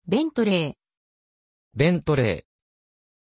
Pronunciation of Bentley
pronunciation-bentley-ja.mp3